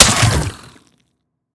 biter-death-5.ogg